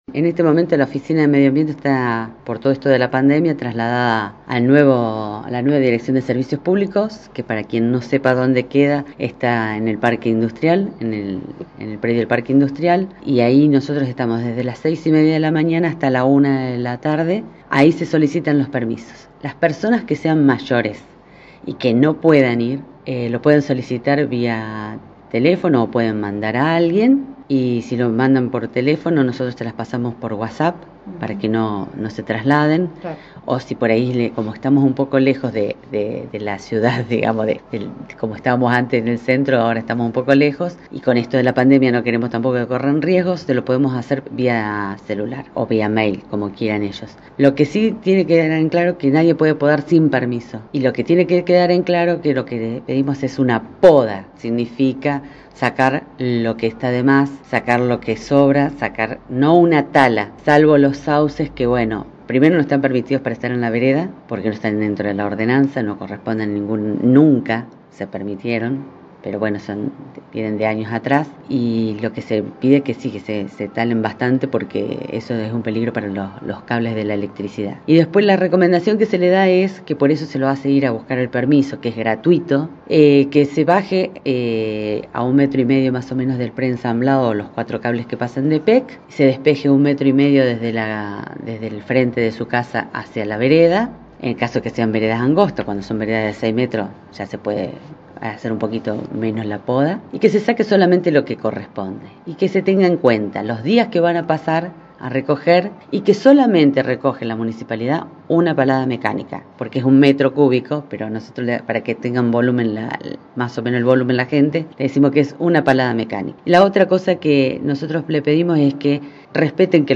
En conversaciones con La Mañana, la titular del área ambiental, Elsa Di Cristófaro, explicó cómo gestionar el permiso, precisó días de poda y recolección de restos, entre otros detalles.